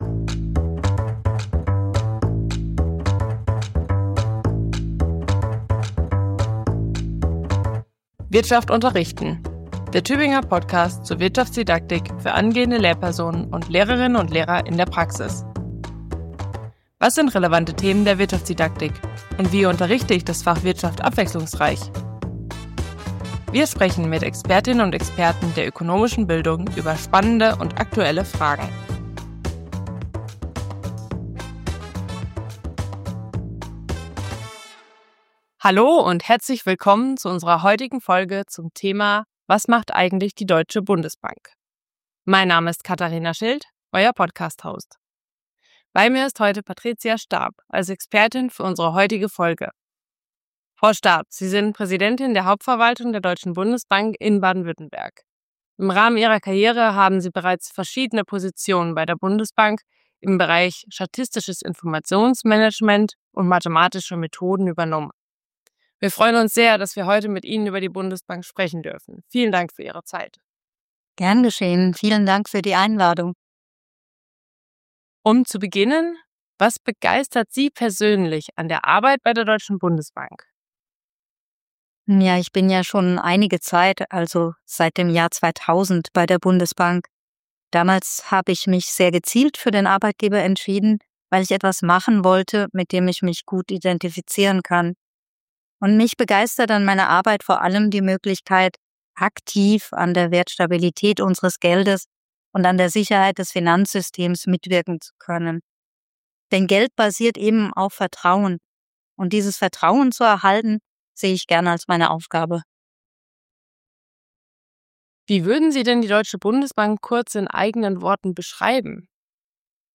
In der elften Folge des Podcasts Wirtschaft unterrichten sprechen wir mit Patricia Staab, Präsidentin der Hauptverwaltung der Deutschen Bundesbank in Baden-Württemberg, über die Aufgaben und Tätigkeiten der Deutschen Bundesbank.